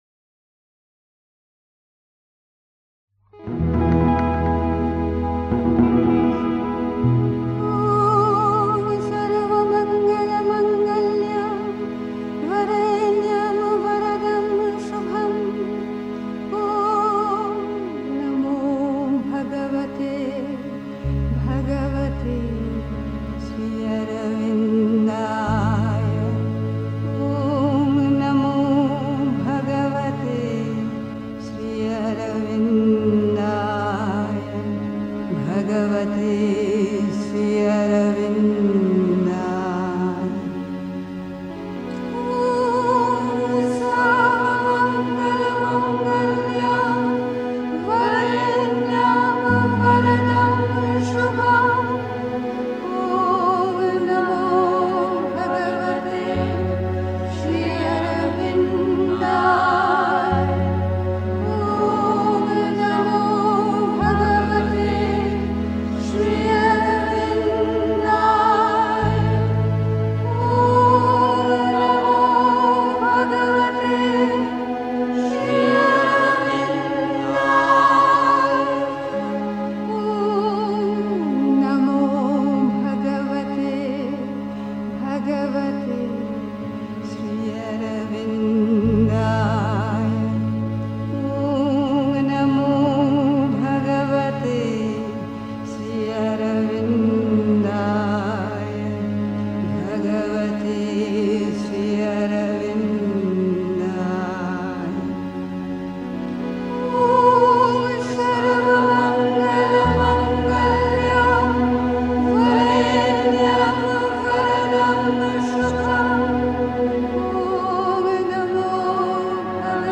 Wenn man außer Fassung gerät (Die Mutter, White Roses, 19 April 1963) 3. Zwölf Minuten Stille.